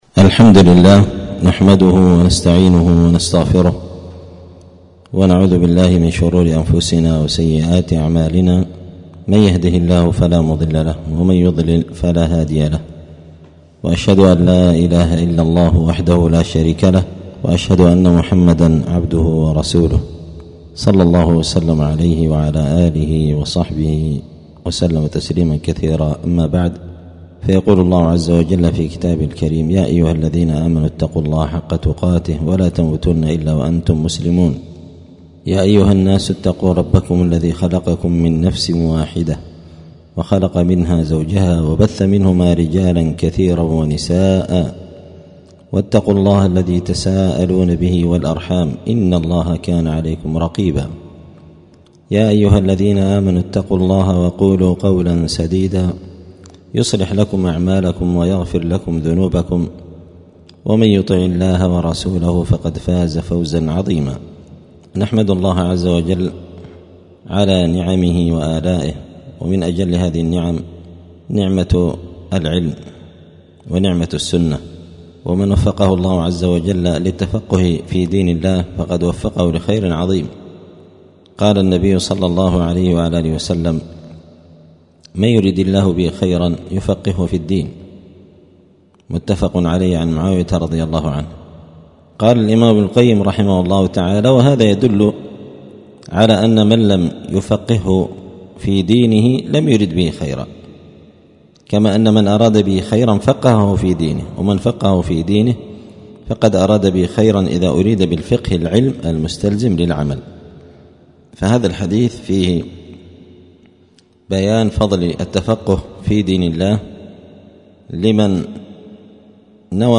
*الدرس الأول (1) {مقدمة مع ترجمة مختصرة للعلامة ابن سعدي رحمه الله}*
دار الحديث السلفية بمسجد الفرقان قشن المهرة اليمن